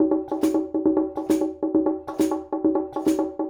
Conga and Hi Hat 02.wav